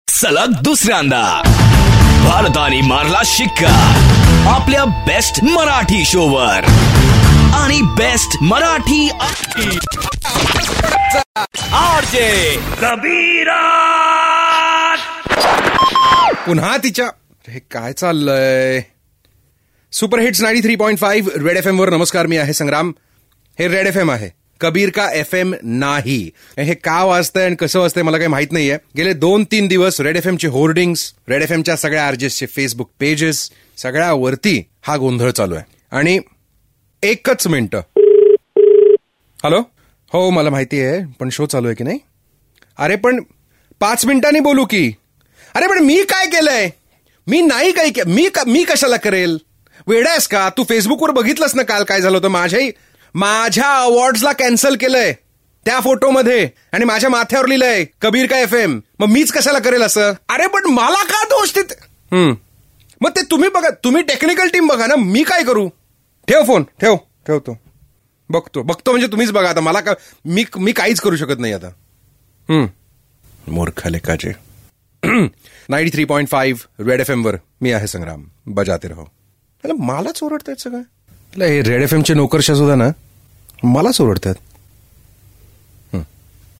India Ka no. 1 Marathi Radio Show.